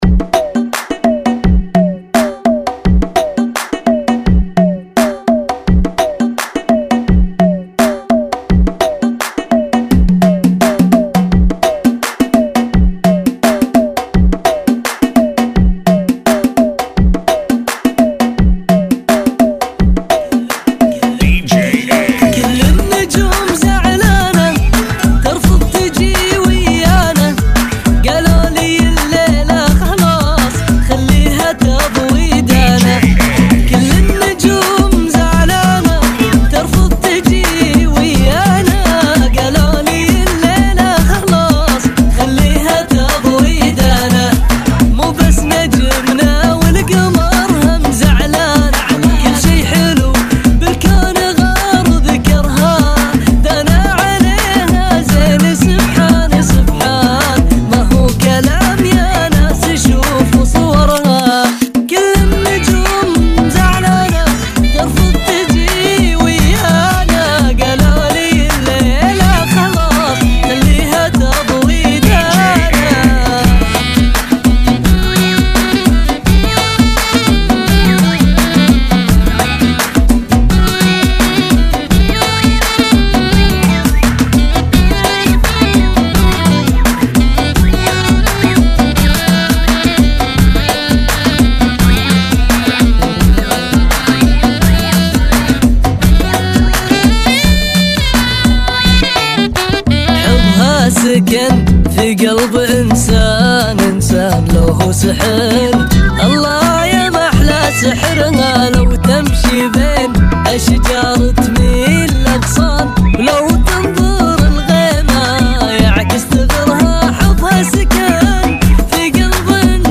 Funky [ 85 Bpm